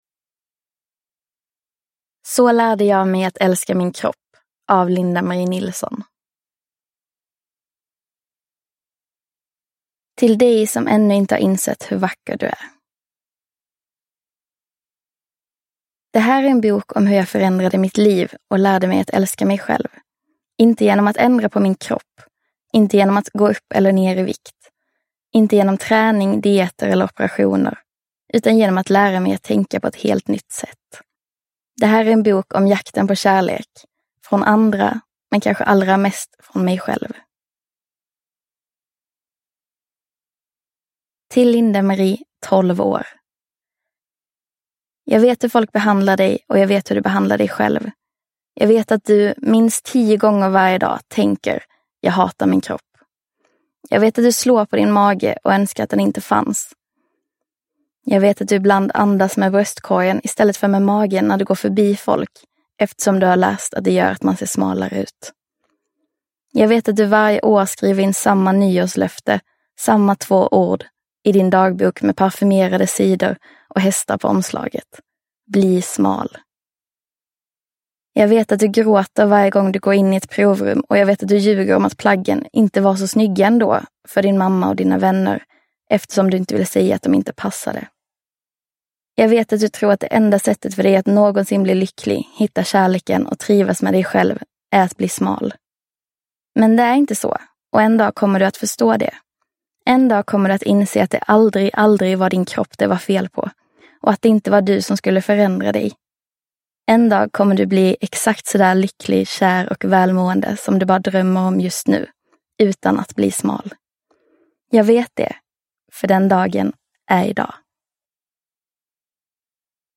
Så lärde jag mig att älska min kropp – Ljudbok – Laddas ner